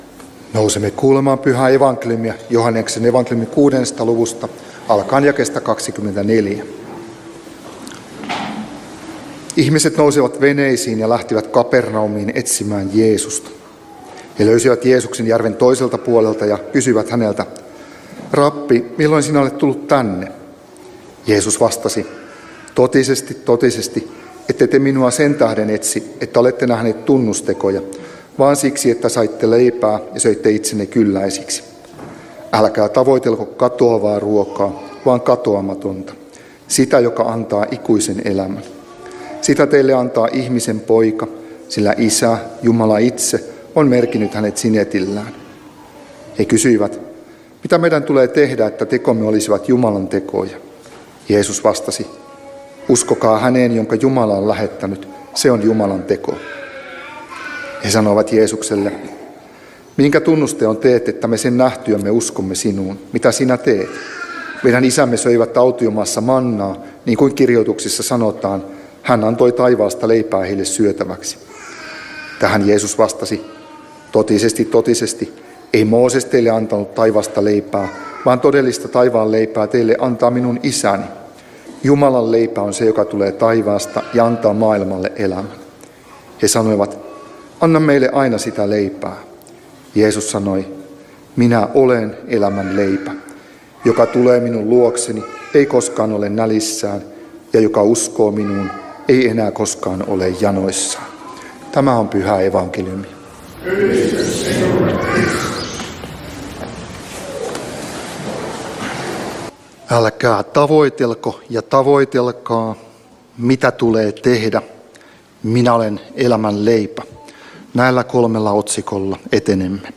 Turku